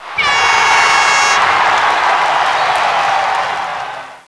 cheer.wav